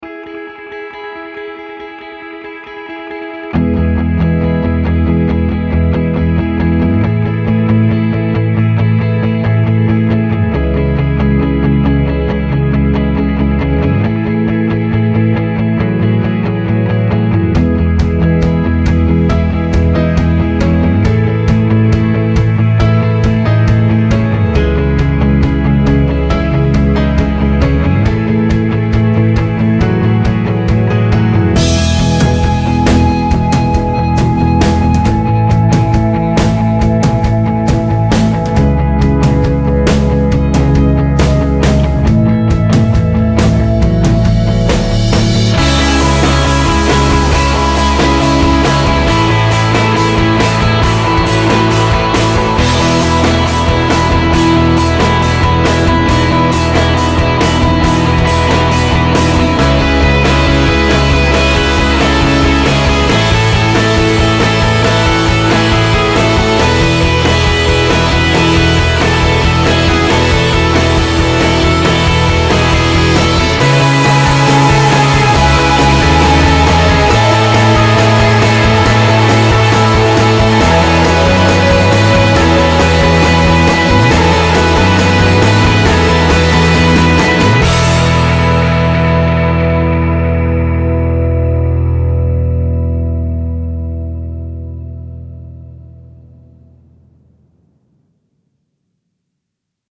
Indie, Rock